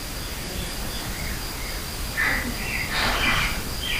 We had left a digital voice recorder on top of a dresser in the bedroom where Lena and Ina Stillinger were killed.
We were all in the external building, letting several voice recorders run for this "static" EVP session. While we were gone, a clear young child's voice was captured saying what sounds to me like a very relevant name :) I've amplifed this clip so you can hear the fainter adult male voice that speaks right before the child's. I don't know for sure what he's saying. Also, immediately after the clear child's voice is a very loud and aggressive voice that I can't quite decipher.